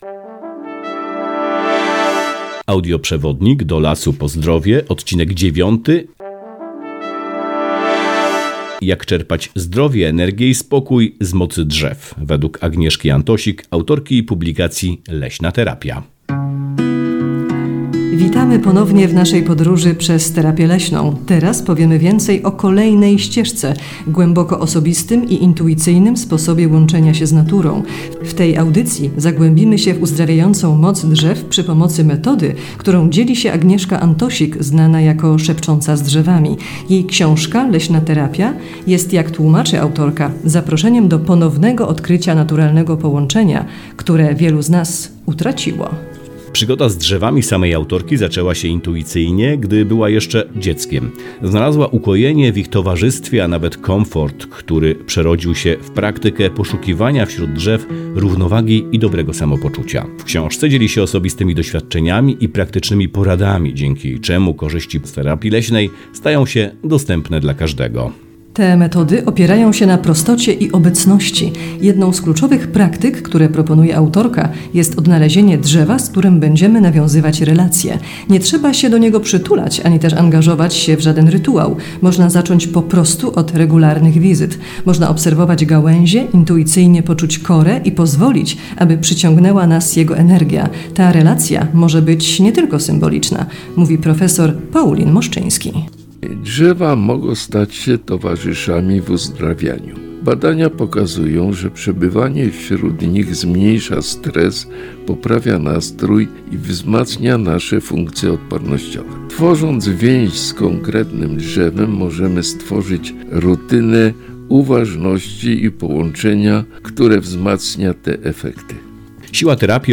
Tablica 9 Lasoterapia, jak czerpać energię - AUDIOPRZEWODNIK Nadleśnictwo Brzesko